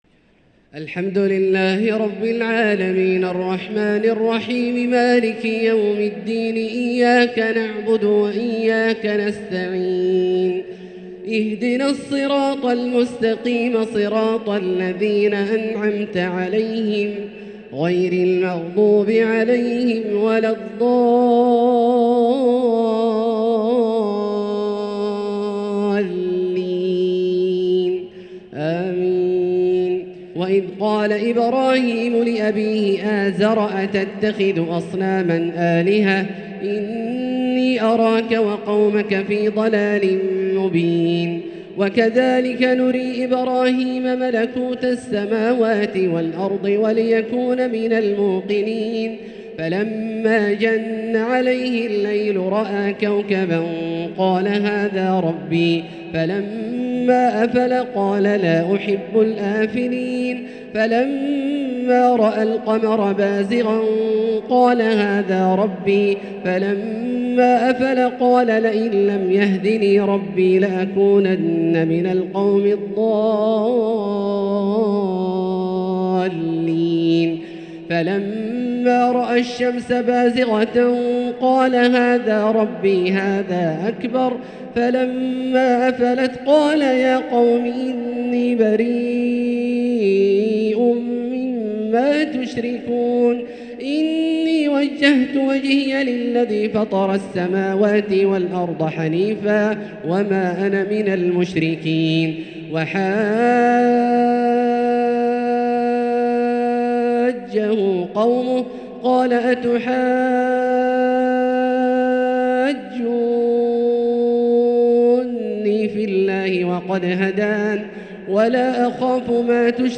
تراويح ليلة 10 رمضان 1444 من سورة الأنعام (74-140) |taraweeh 10st niqht ramadan Surah Al-Anaam 1444H > تراويح الحرم المكي عام 1444 🕋 > التراويح - تلاوات الحرمين